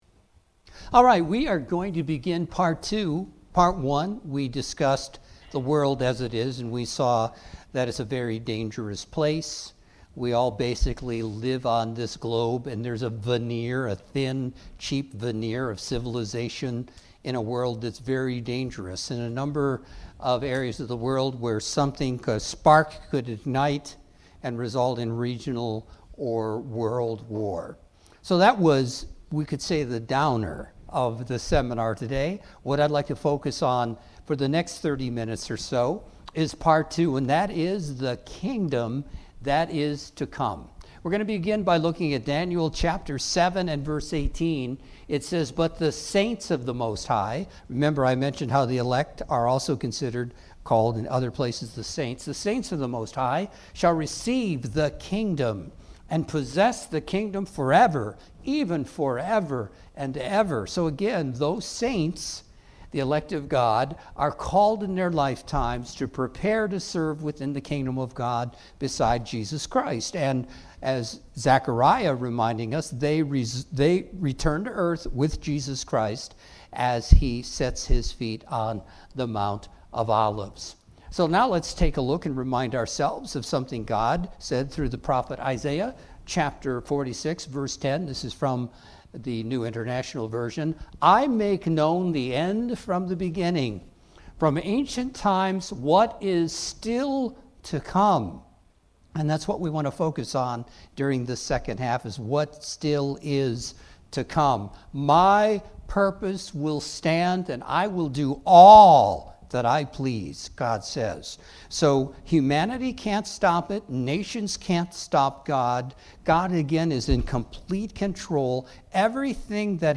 Seminar - The Kingdom of God - Part 2
Local Kingdom of God Seminar offered to Beyond Today subscribers in the greater Cleveland area.